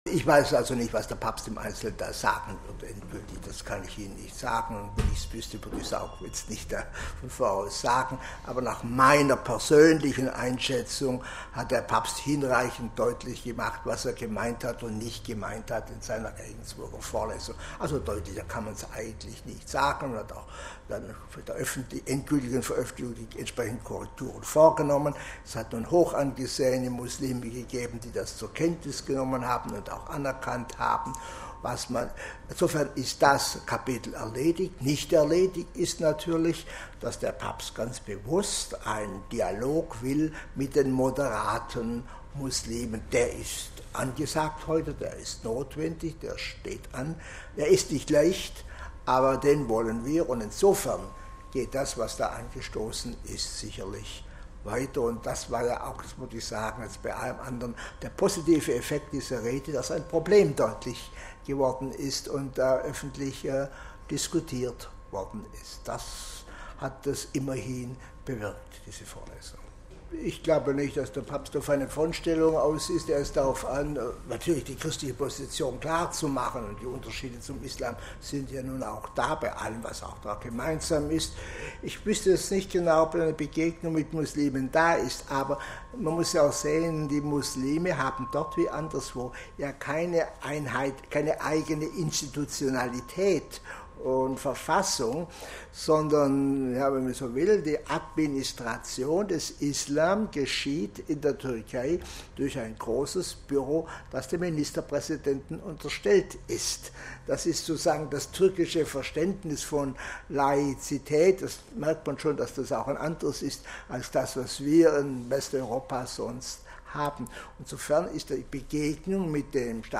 Kurz vor der Reise äußerte er sich bei einem Pressetermin bei Radio Vatikan zur Reise Benedikts an den Bosporus. Hören Sie hier seine Äußerungen auf Journalistenfragen zur umstrittenen Rede des Papstes in Regensburg mit ihren vermeintlich islamfeindlichen Wendungen. Weiteres Thema: Wie läuft der katholisch-islamische Dialog?